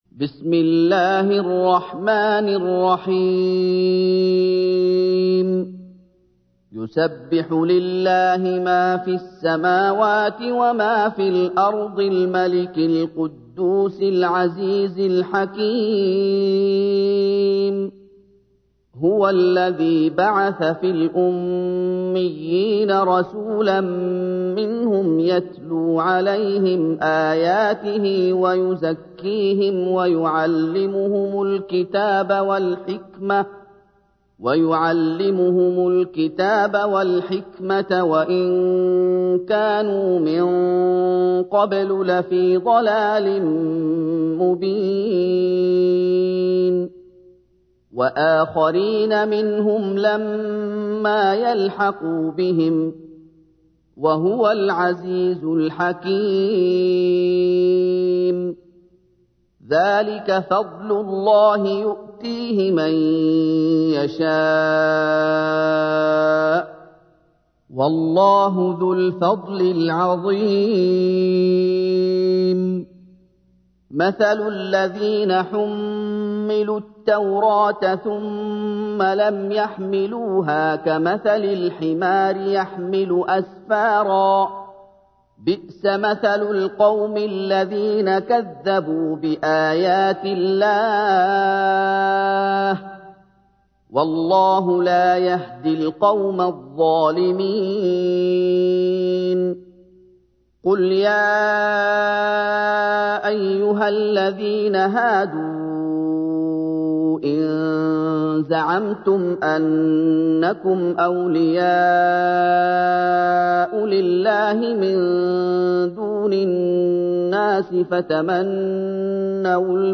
تحميل : 62. سورة الجمعة / القارئ محمد أيوب / القرآن الكريم / موقع يا حسين